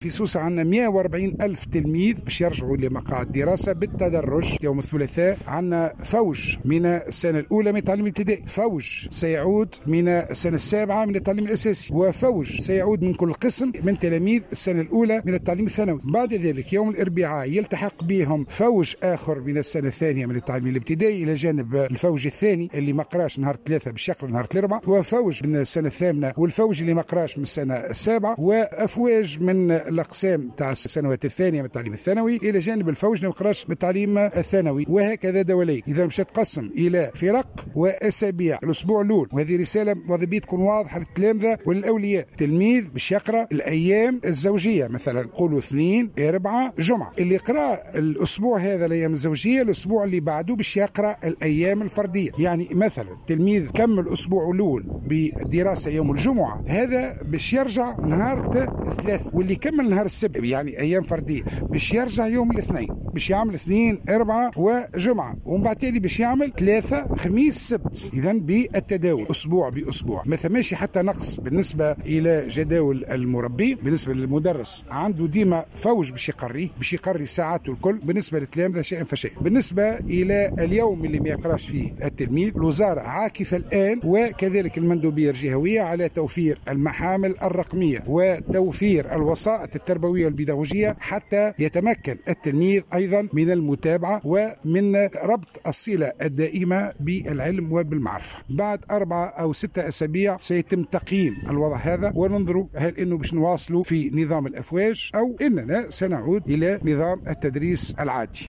وبيّن في تصريح لجوهرة أف أم، أن التلاميذ الذي سيدرسون في الأسبوع الأول خلال الأيام الفردية (الثلاثاء، الخميس، السبت)، سيدرسون في الأسبوع الموالي خلال الأيام الزوجية (الاثنين، الأربعاء، الجمعة)، والعكس بالعكس.